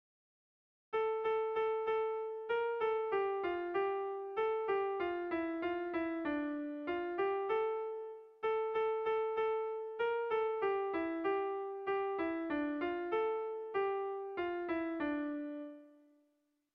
Irrizkoa
Lauko handia (hg) / Bi puntuko handia (ip)
A1A2